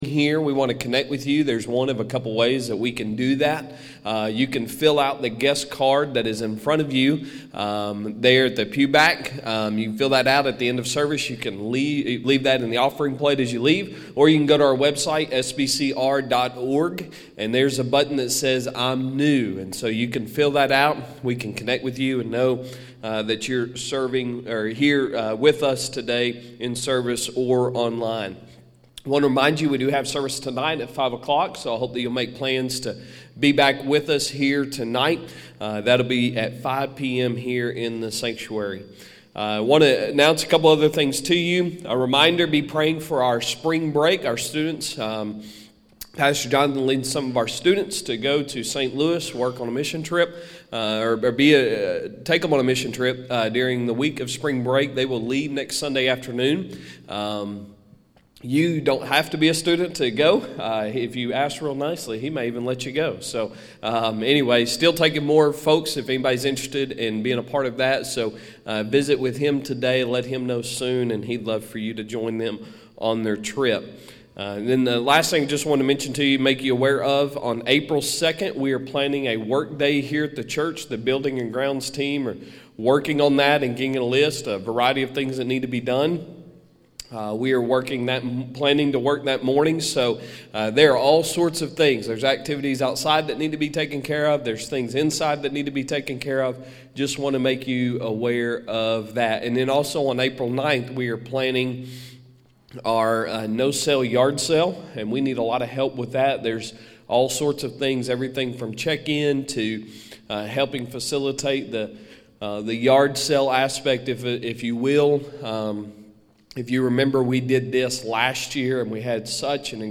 Sunday Sermon March 13, 2022